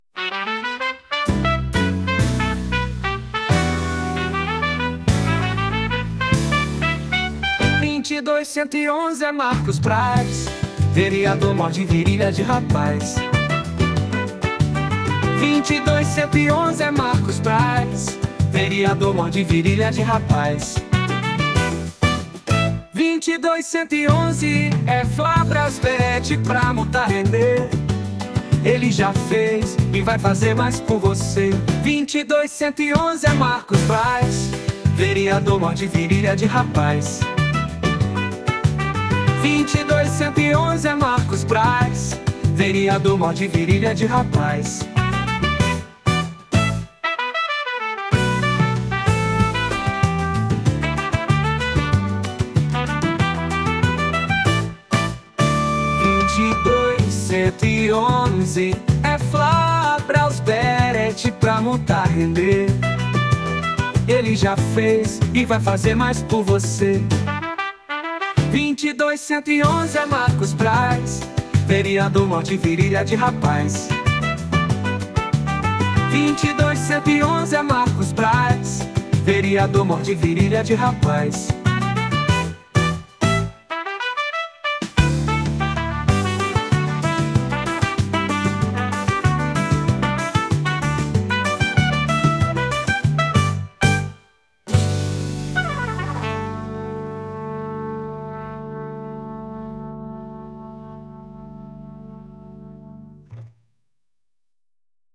Marcos Braz (PL) se envolveu em uma briga e mordeu a virilha de um torcedor do Flamengo que o xingou. Pois não é que criaram um jingle sobre isso…